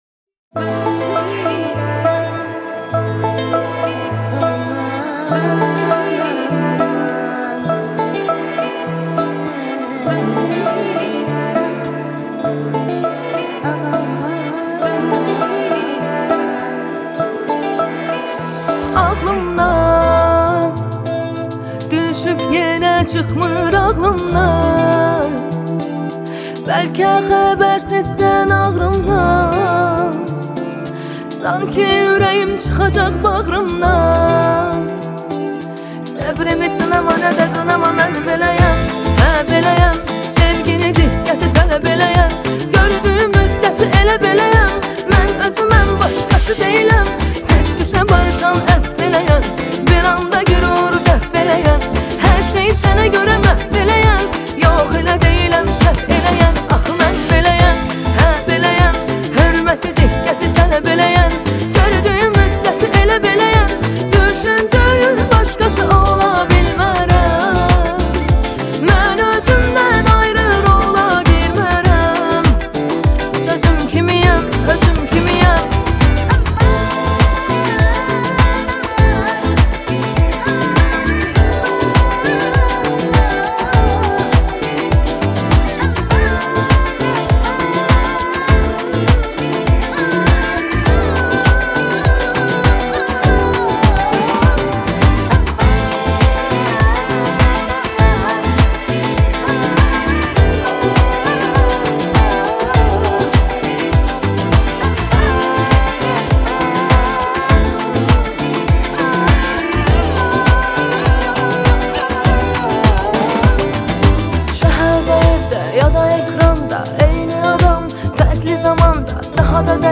آهنگ آذربایجانی آهنگ شاد آذربایجانی آهنگ هیت آذربایجانی